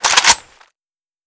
Index of /fastdl/sound/weapons/ak103